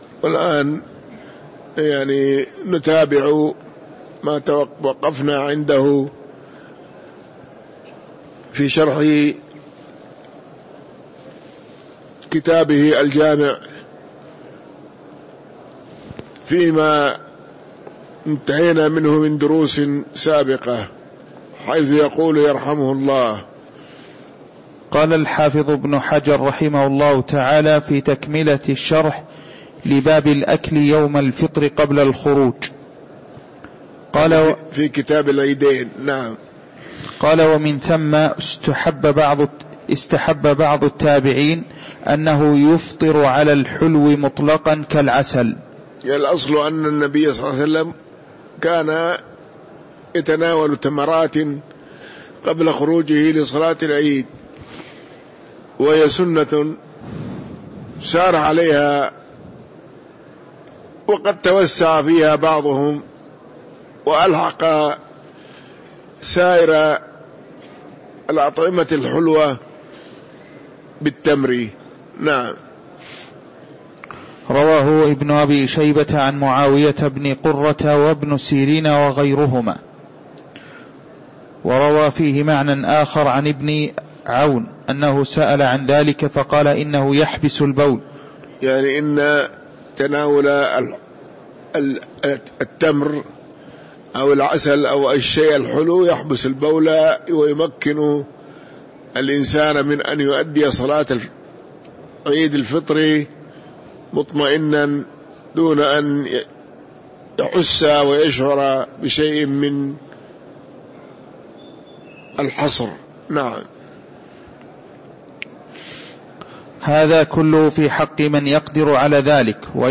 تاريخ النشر ١٢ رجب ١٤٤٣ هـ المكان: المسجد النبوي الشيخ